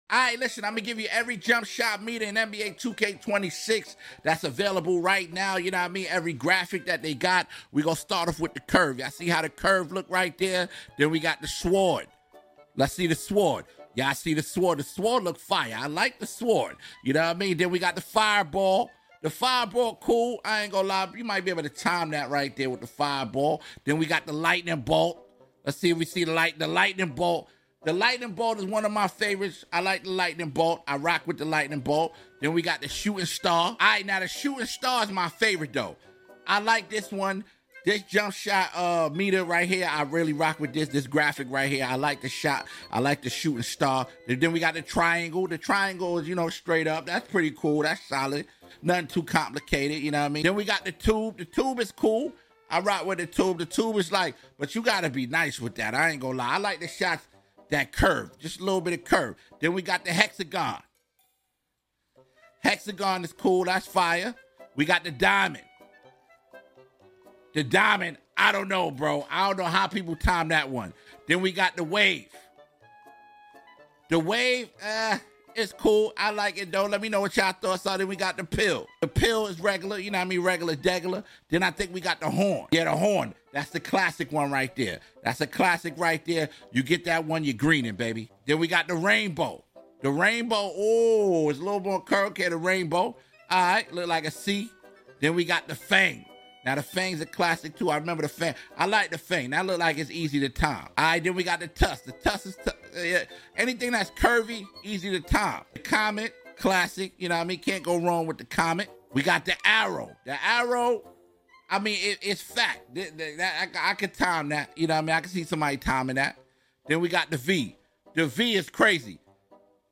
Every jumpshot meter in NBA sound effects free download